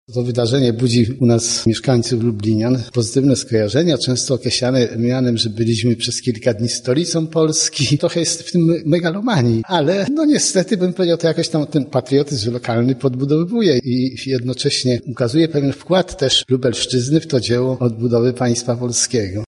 wykład-o-daszyńskim-wbp-1.mp3